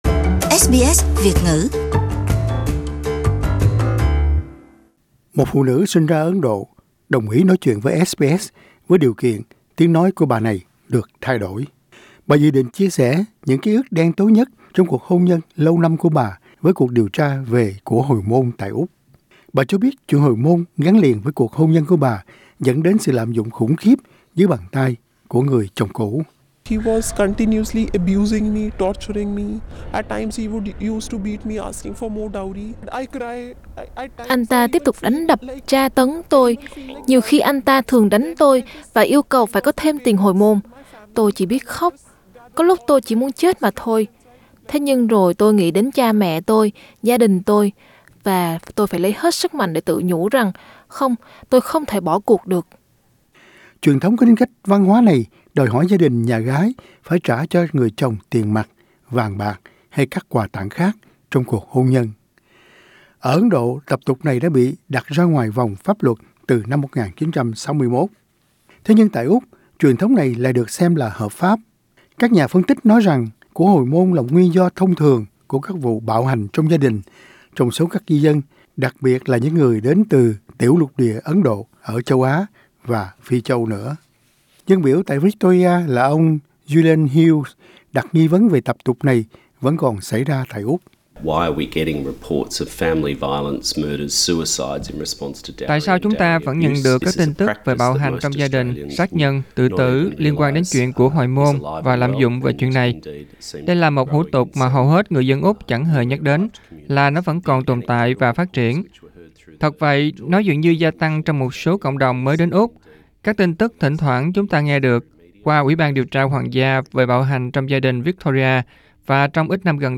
A dowry-abuse victim reflects Source: SBS